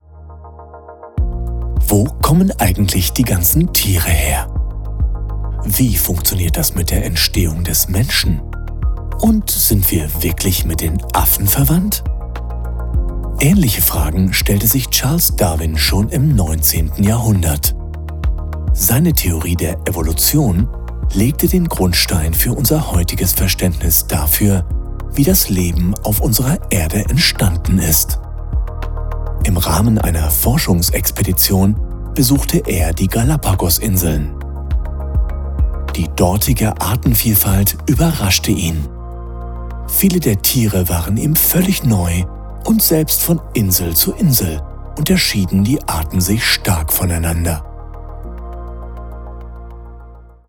Commercieel, Diep, Opvallend, Vertrouwd, Warm
E-learning